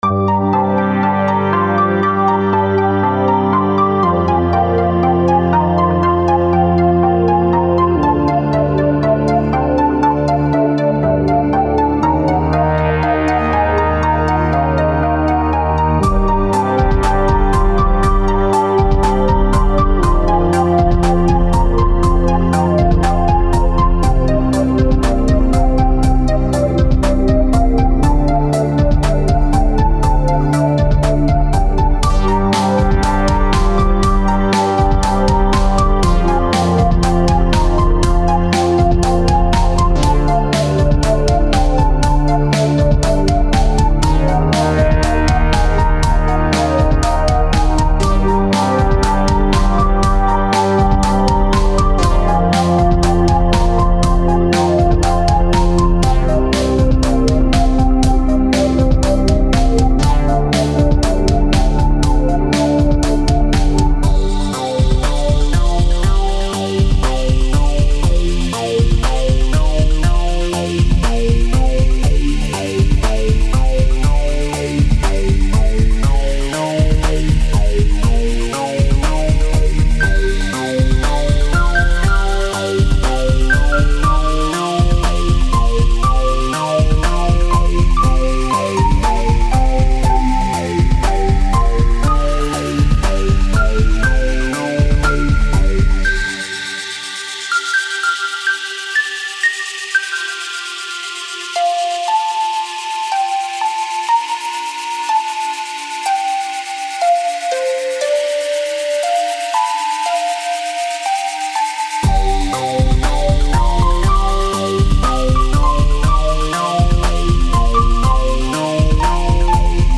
Even though the panning was a little strong for listening with headphones, the arrangement is ace and I love the little details like delay. Maybe the phat beatz didn't fit the theme too well, though.